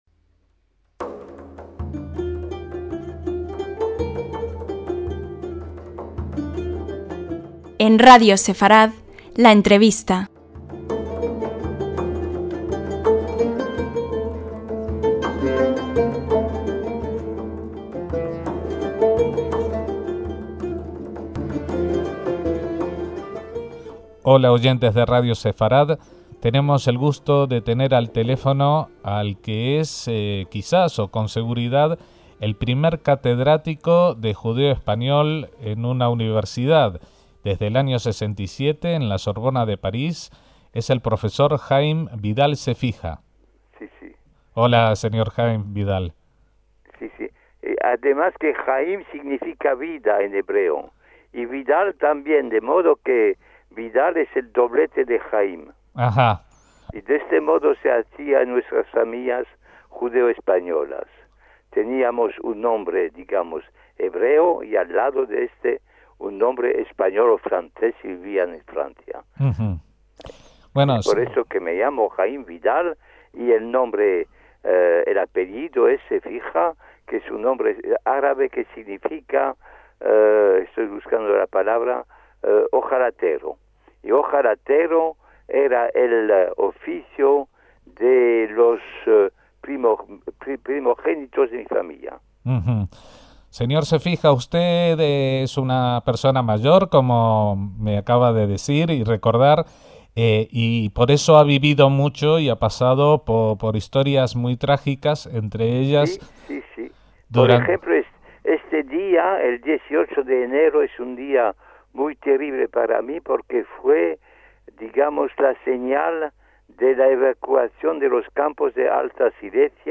una entrevista exclusiva